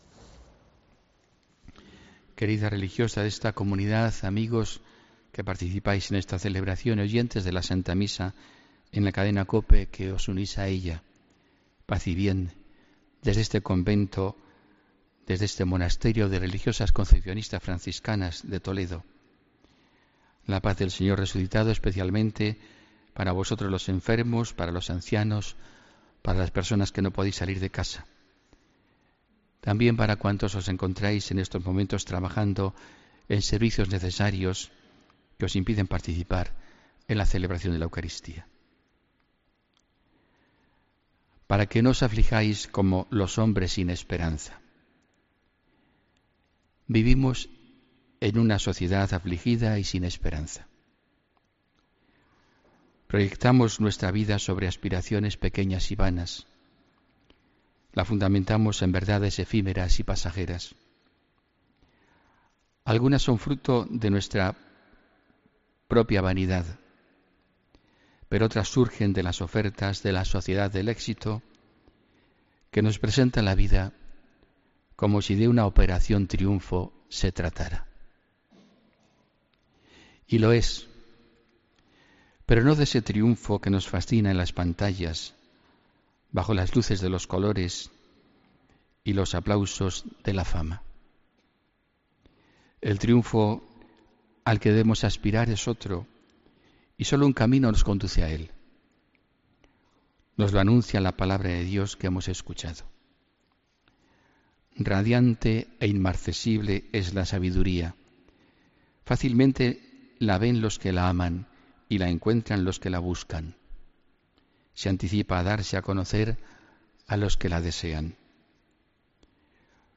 HOMILÍA 12 NOVIEMBRE DE 2017